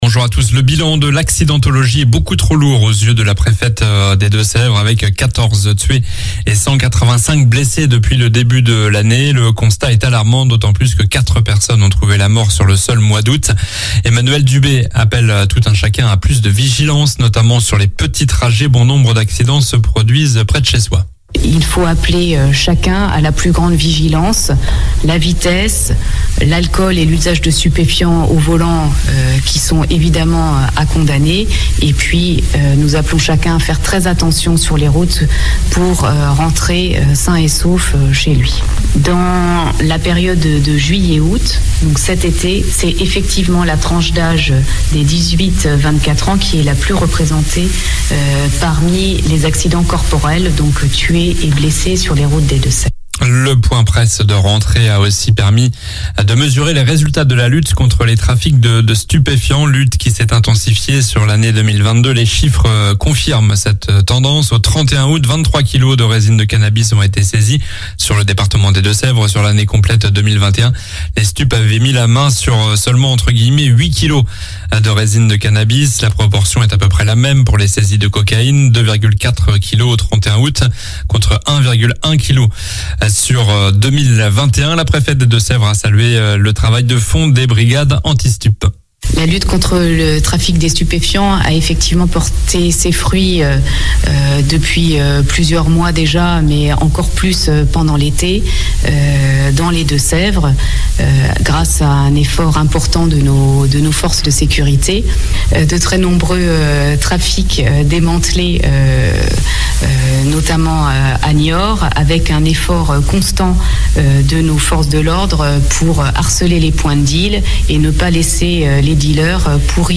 Journal du lundi 12 septembre (matin)